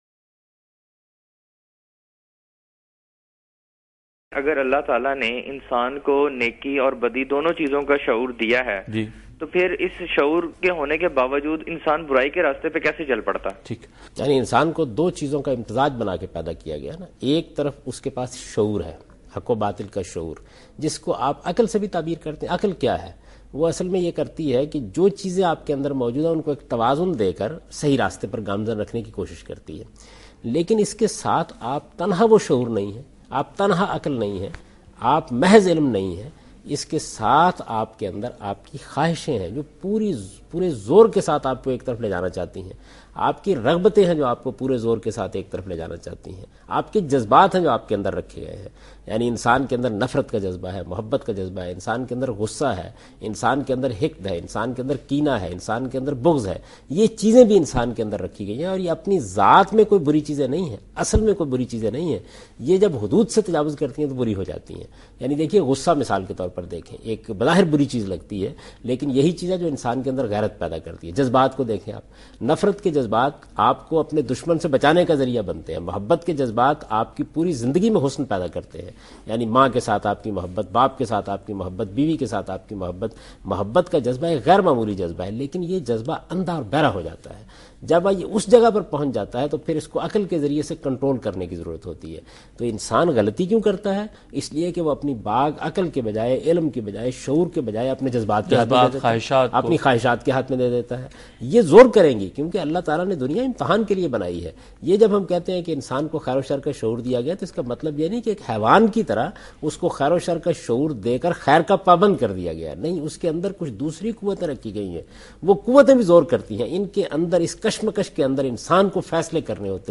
In this video Javed Ahmad Ghamidi Answers a question regarding Reason and Passion in program Deen o Daanish on Dunya News.
جاوید احمد غامدی دنیا نیوز کے پروگرام دین و دانش میں ارادہ اور اختیار سے متعلق ایک سوال کا جواب دے رہے ہیں۔